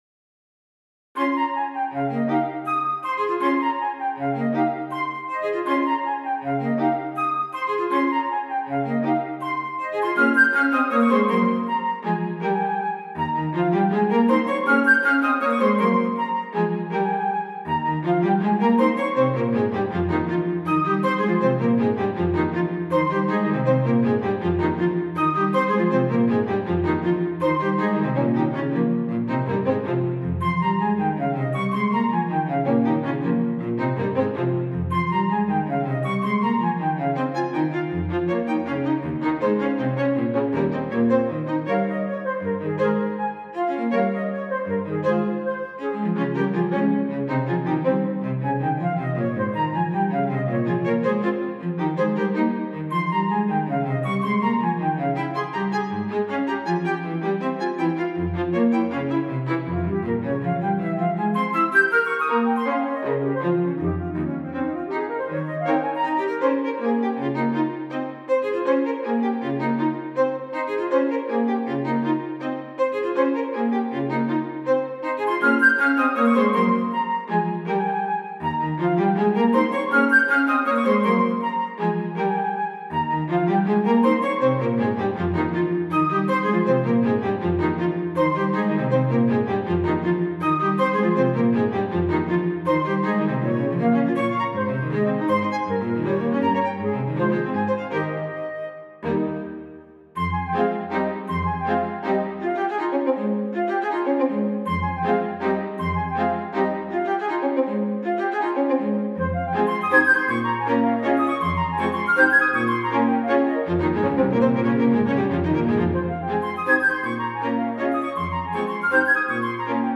Minuet for Flute Quartet in Ab Major
This is a little minuet I wrote a few years ago. I was going for a very Classical period feel with it. Update: changed the heavy part in the middle section and rebalanced the instruments .